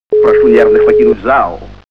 При прослушивании Золотой теленок - Прошу нервных покинуть зал качество понижено и присутствуют гудки.